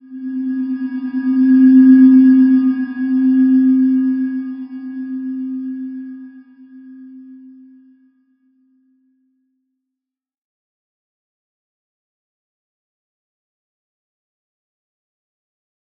Slow-Distant-Chime-C4-f.wav